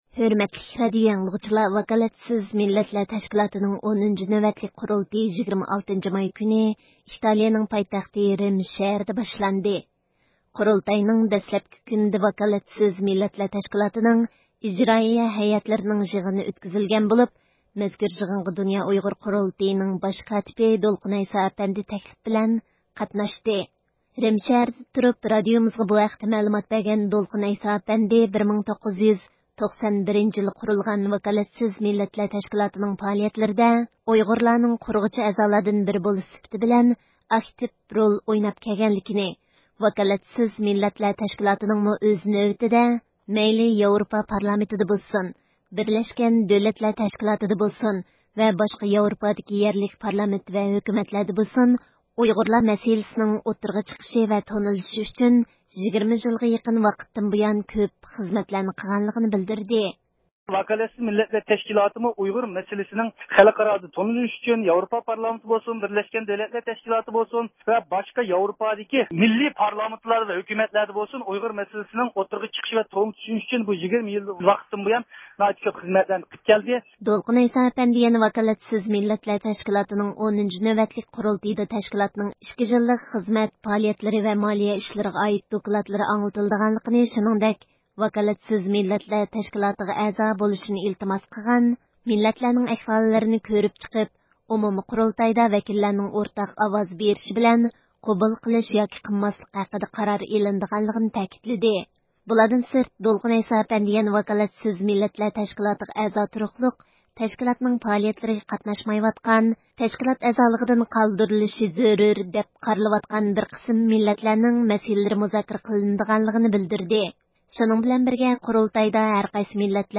رىم شەھىرىدە تۇرۇپ رادىئومىزغا بۇ ھەقتە مەلۇمات بەرگەن دولقۇن ئەيسا ئەپەندى، 1991 - يىلى قۇرۇلغان ۋاكالەتسىز مىللەتلەر تەشكىلاتىنىڭ پائالىيەتلىرىدە، ئۇيغۇرلارنىڭ قۇرغۇچى ئەزالاردىن بىرى بولۇش سۈپىتى بىلەن ئاكتىپ رول ئويناپ كەلگەنلىكىنى، ۋاكالەتسىز مىللەتلەر تەشكىلاتىنىڭمۇ ئۆز نۆۋىتىدە، مەيلى ياۋرۇپا پارلامېنتىدا بولسۇن، بىرلەشكەن دۆلەتلەر تەشكىلاتىدا بولسۇن ۋە باشقا ياۋروپادىكى يەرلىك پارلامېنت ۋە ھۆكۈمەتلەردە بولسۇن، ئۇيغۇر مەسىلىسىنىڭ ئوتتۇرىغا چىقىشى ۋە تونۇلۇشى ئۈچۈن 20 يىلغا يېقىن ۋاقىتتىن بۇيان كۆپ خىزمەتلەرنى قىلغانلىقىنى بىلدۈردى.